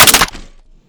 weap_med_gndrop_2.wav